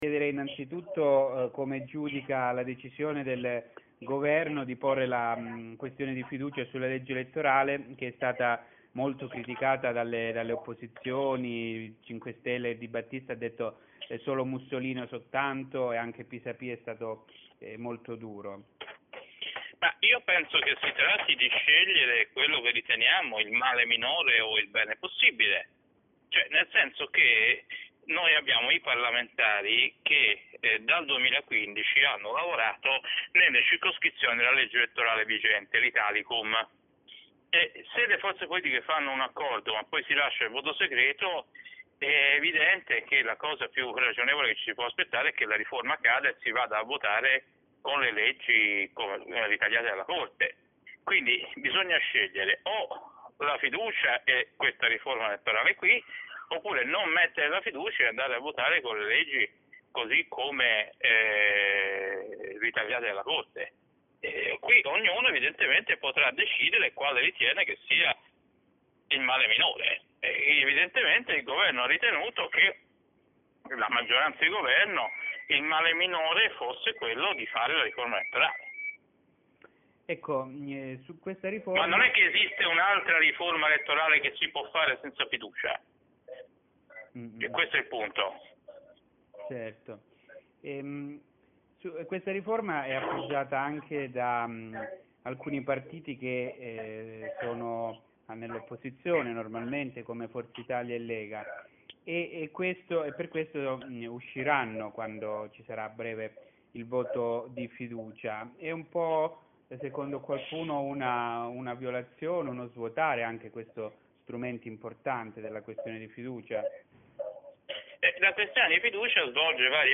Abbiamo raccolto l’opinione del costituzionalista ed ex senatore Stefano Ceccanti. In coda il sonoro dell’intervista integrale.